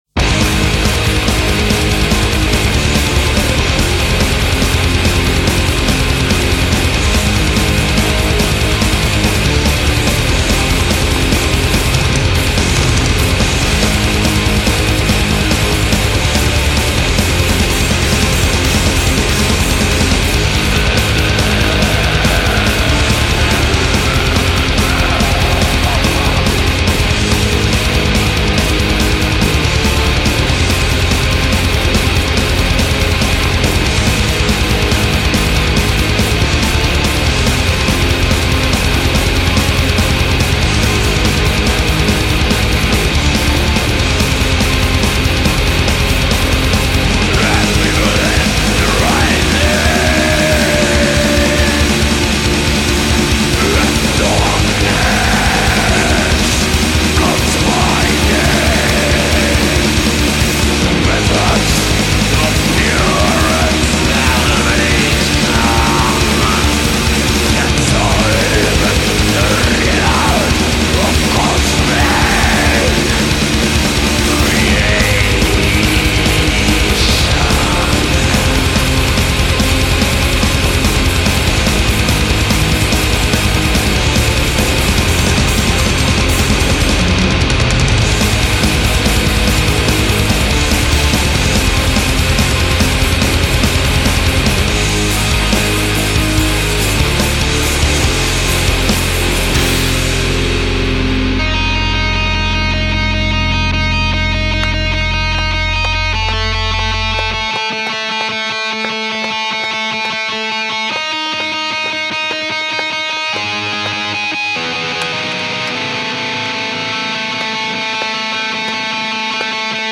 =DEPRESSIVE BLACK METAL=